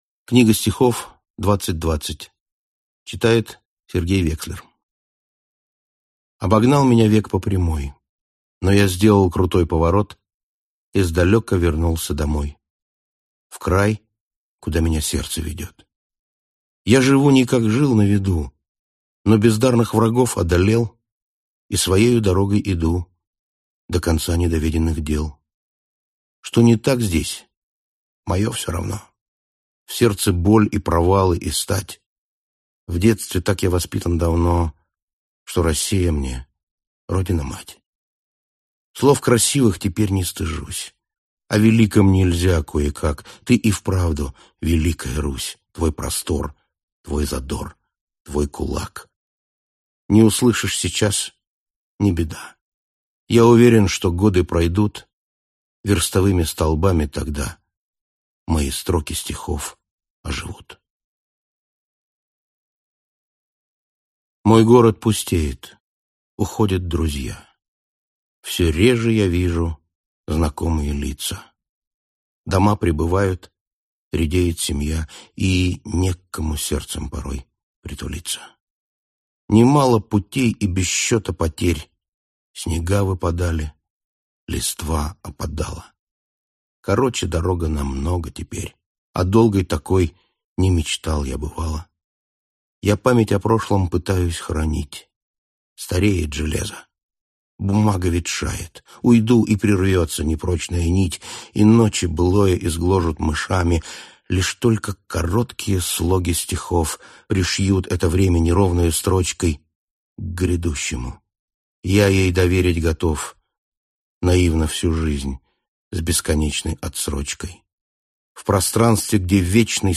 Аудиокнига 2020. Книга стихов | Библиотека аудиокниг